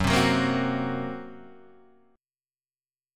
F# Major Flat 5th